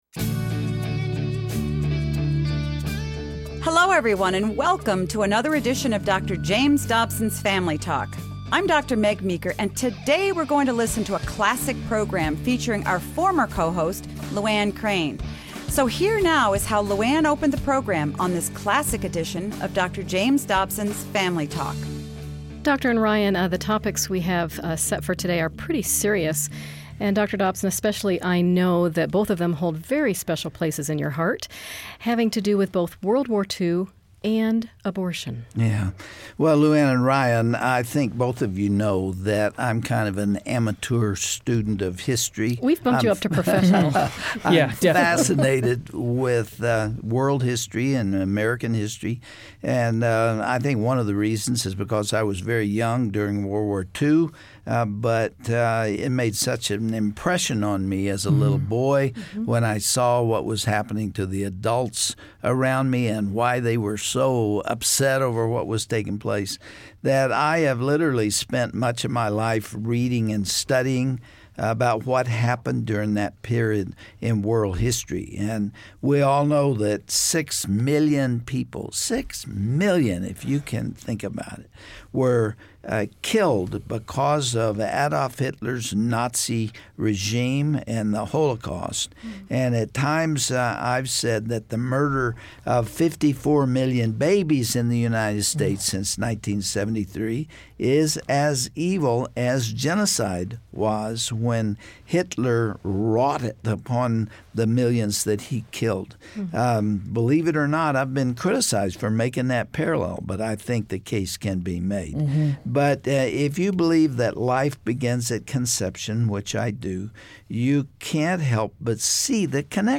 On the next edition of Family Talk, Dr. James Dobson interviews Ray Comfort regarding abortion as Americas Holocaust.